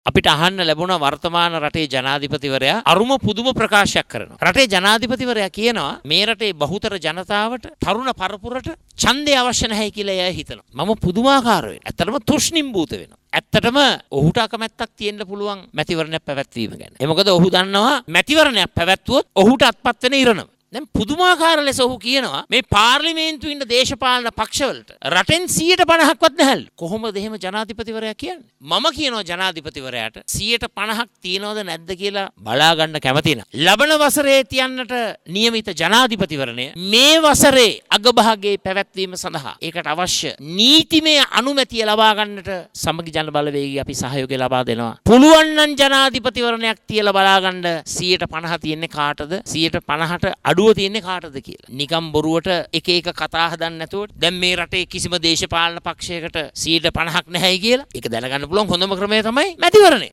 මෙරට දේශපාලන පක්ෂ සදහා සියයට 50 ඡන්ද පදනමක් ලබා ගැනීමට හැකියාව තිබේද නැද්ද යන්න පිළිබඳ දැන ගැනීමට ජනාධිපතිවරණය මෙම වසරේදී පවත්වන ලෙස ජනාධිපතිවරයාට සදහන් කරන බවයි උද්දකන්දර ප්‍රදේශයේ පැවති වැඩසටහනකට එක්වෙමින් විපක්ෂ නායකවරයා සදහන් කළේ.